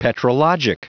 Prononciation du mot petrologic en anglais (fichier audio)
Prononciation du mot : petrologic